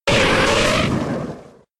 Cri de Ponyta K.O. dans Pokémon X et Y.